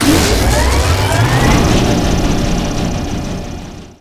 droneout.wav